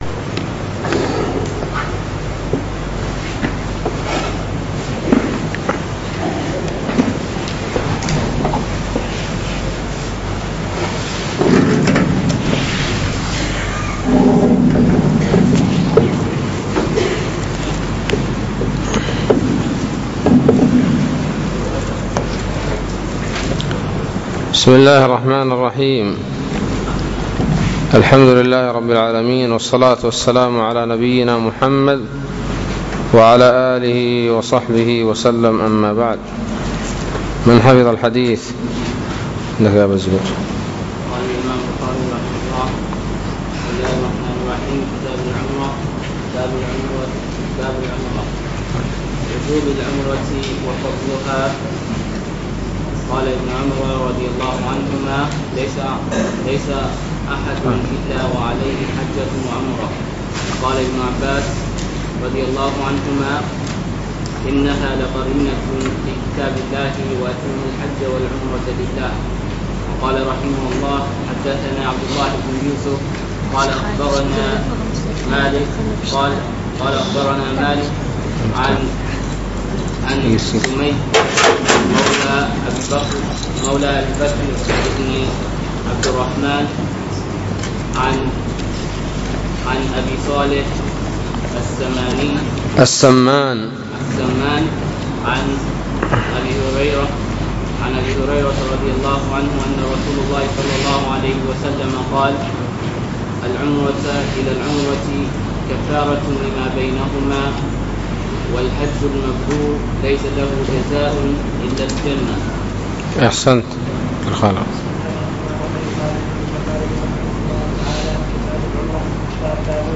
الدرس الثاني : بَاب مَنِ اعْتَمَرَ قَبْلَ الْحَجِّ، و بَاب كَمْ اعْتَمَرَ النَّبِيُّ صلى الله عليه وسلم(1)